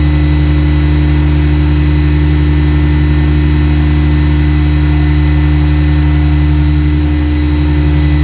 rr3-assets/files/.depot/audio/sfx/electric/dezir_low.wav